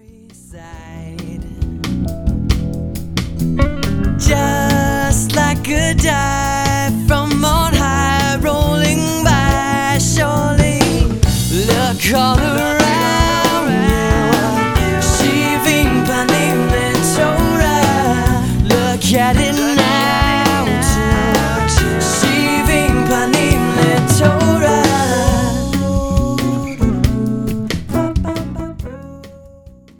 a contemporary sound
addictive melodies and driving rhythms